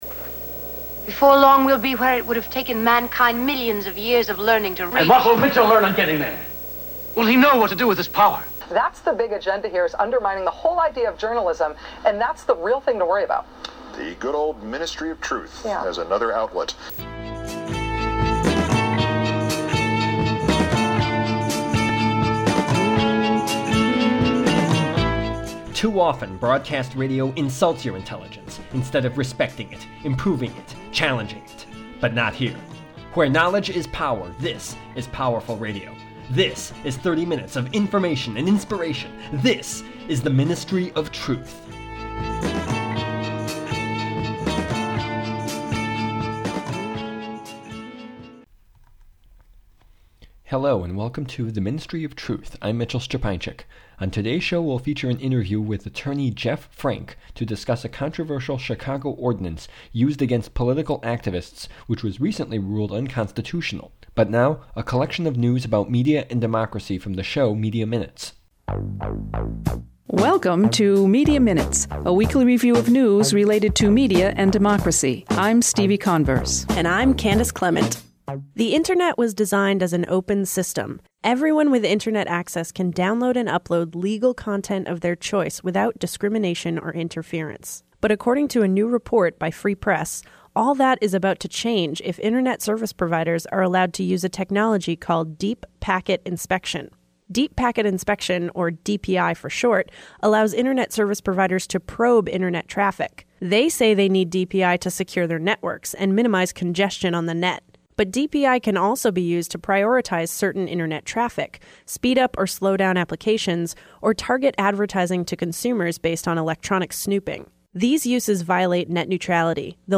The Ministry of Truth: Interview
Tags: radio